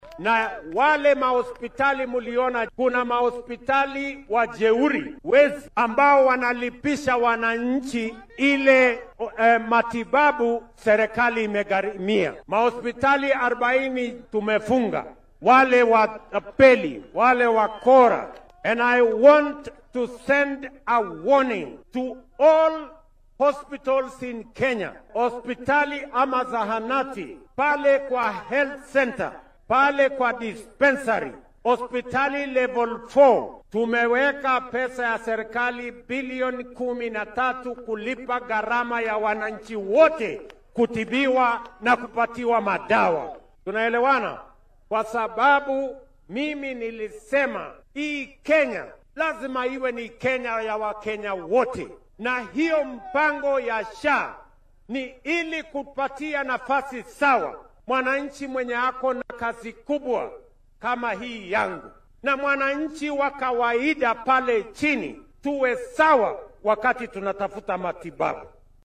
Ruto oo ka hadlayay munaasabadda awoodsiinta dhallinyarada iyo ganacsatada ismaamulka Nairobi oo lagu qabtay aqalka madaxtooyada ee State House ayaa sheegay in bisha soo socoto dowladda iyo bangiga adduunka oo iskaashanaya ay ilaa 5 bilyan oo shilin ku bixin doonaan in min 50,000 oo shilin la siiyo ilaa 100,000 oo dhallinyaro ah.